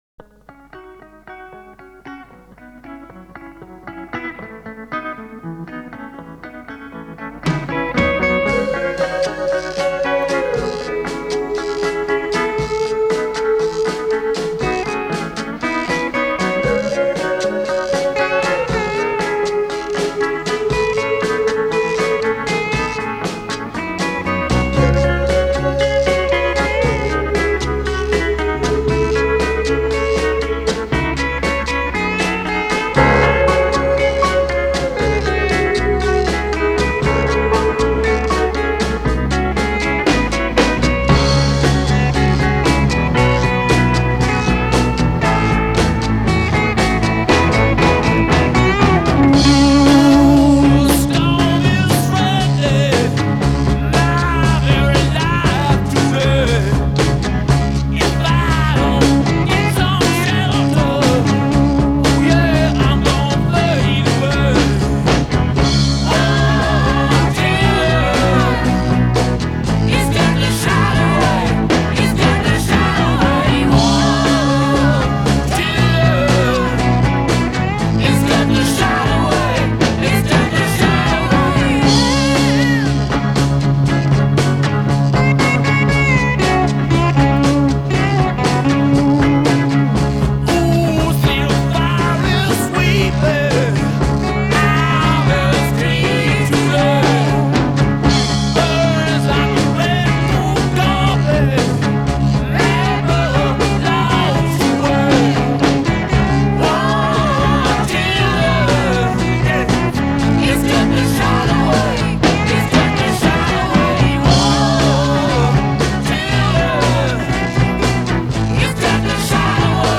Blues Rock, Hard Rock, Country Rock, Classic Rock